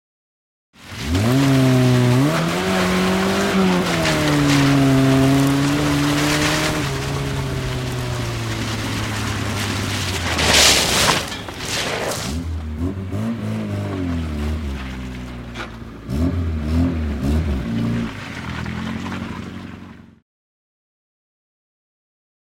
Звук разгона Лады с места